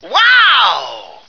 flak_m/sounds/male2/est/M2ohyeah.ogg at df55aa4cc7d3ba01508fffcb9cda66b0a6399f86